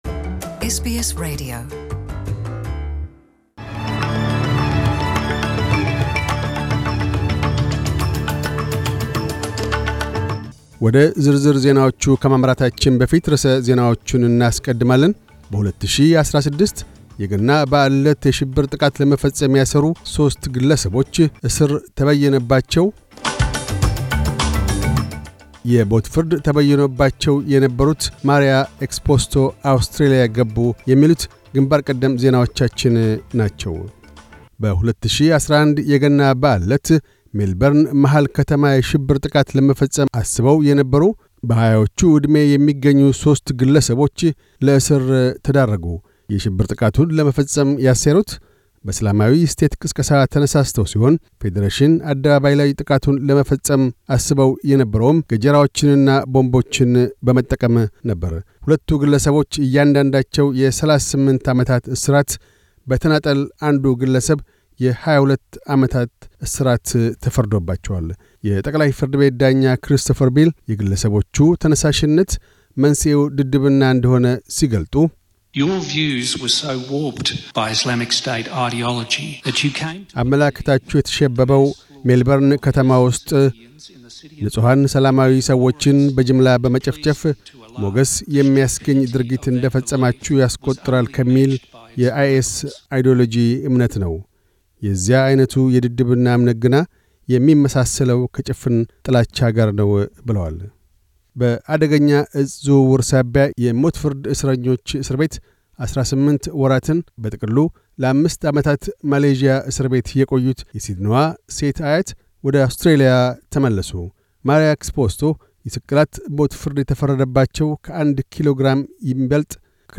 News Bulletin 2911